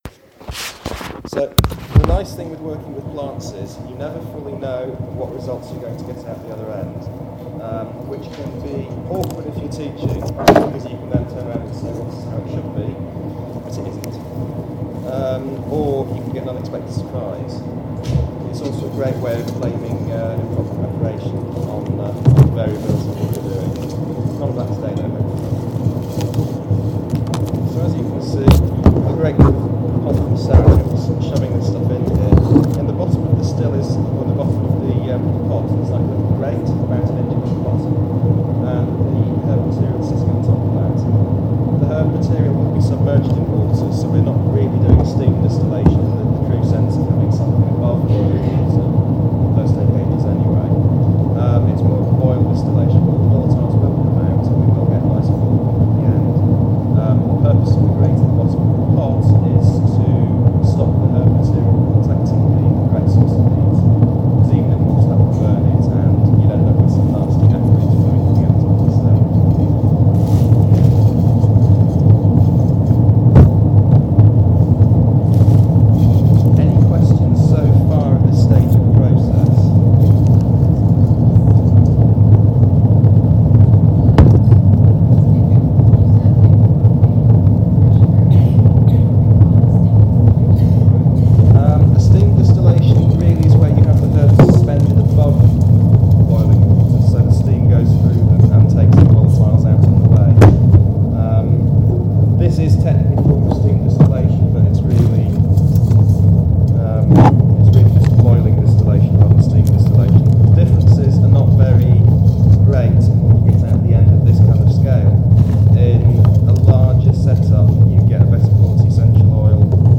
This workshop started informally: I was still setting up equipment when people started coming into the talk early and there was no definite start. The recording starts about a minute into the talk, hence the rather abrupt nature of the recording and this transcript.
* End of formal talk – the Meadowsweet floral water, a tincture, and some of the residue from the still (strong tea) are passed round for smelling, then tasting by the audience and more questions are asked.